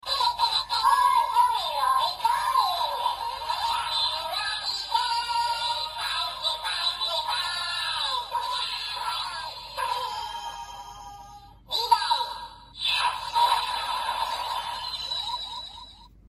Revi饱藏音效.MP3